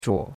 zhuo3.mp3